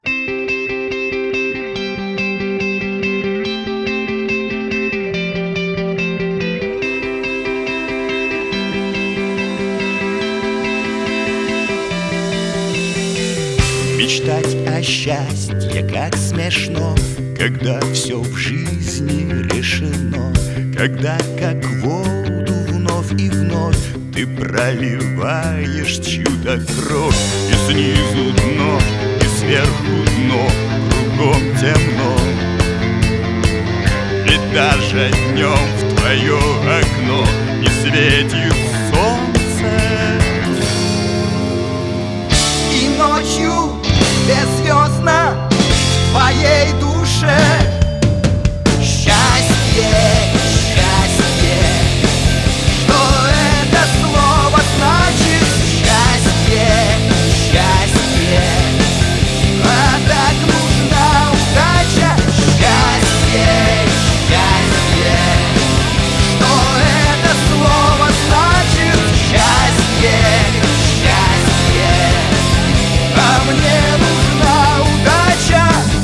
• Качество: 320, Stereo
мужской голос
громкие
электрогитара
фолк-рок
хоррор-панк